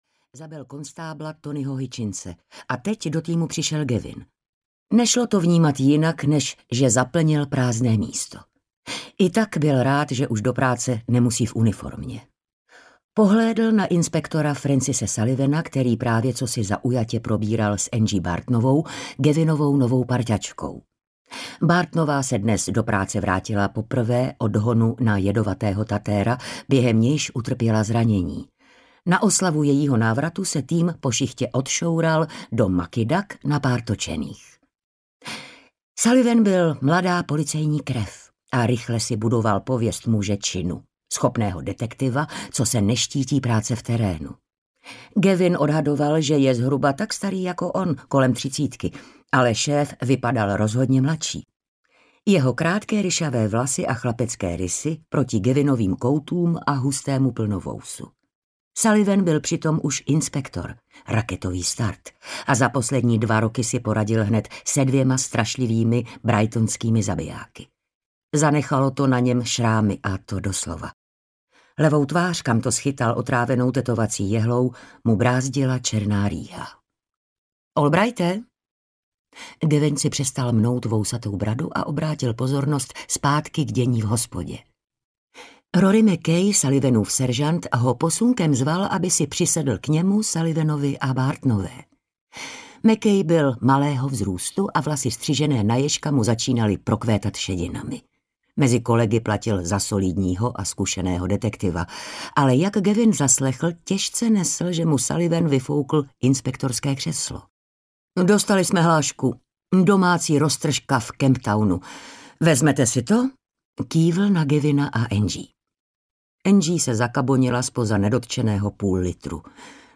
Balzamovač audiokniha
Ukázka z knihy